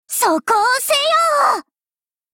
贡献 ） 协议：Copyright，其他分类： 分类:碧蓝航线:星座语音 您不可以覆盖此文件。